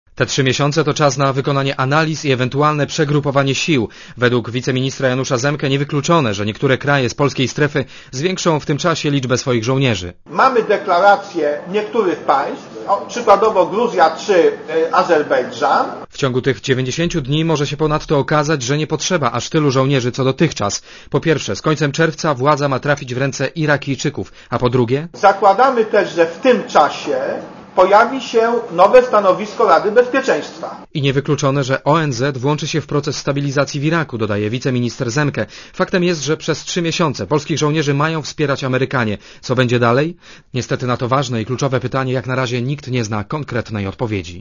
Źródło: PAP relacja reportera Radia ZET Wycofywane kontyngenty z Iraku to 20% potencjału międzynarodowej dywizji.